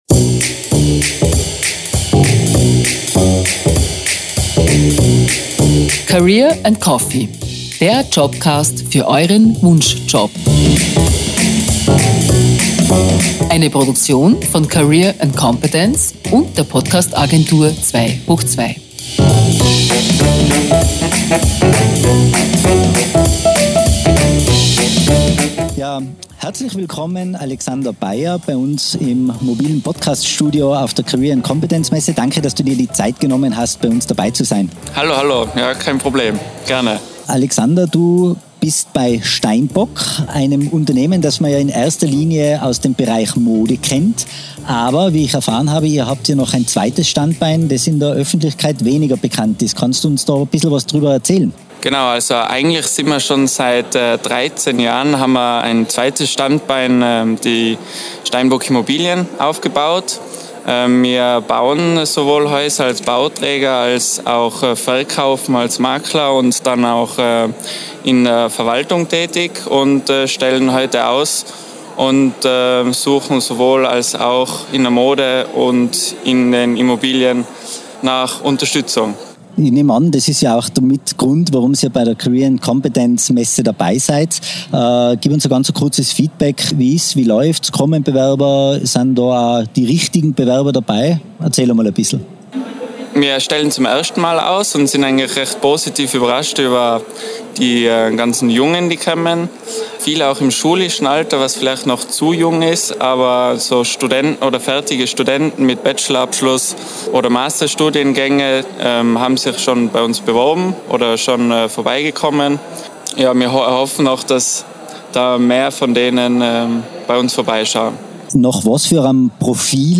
Livemitschnitt von der Career & Competence-Messe mit
Masterlounge in Innsbruck am 4. Mai 2022.